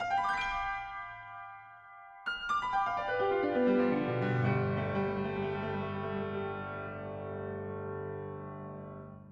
Game rip